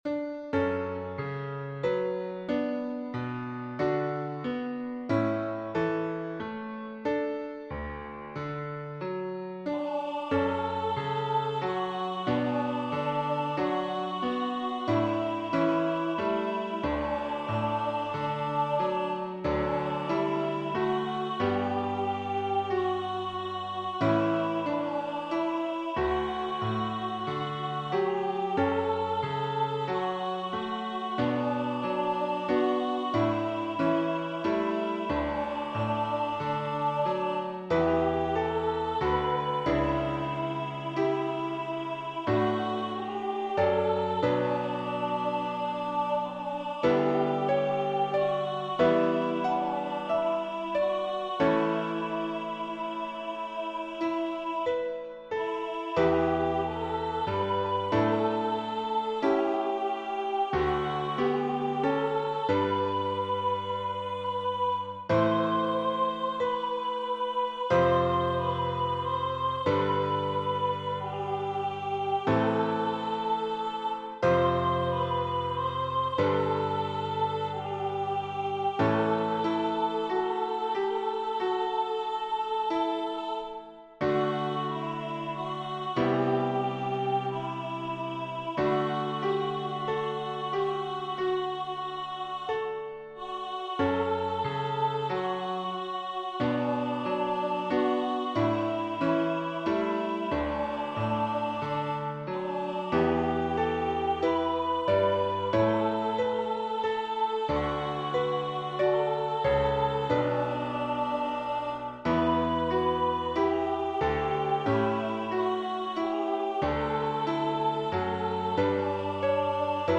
A morning prayer with a gently lilting rhythm. Unison. Piano accompaniment.
The rhythm is irregular, with the 7/4 bars sometimes dividing 3:4 and sometimes 4:3.